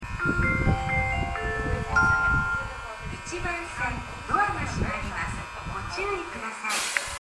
音質はとてもいい です。
発車メロディーフルコーラスです。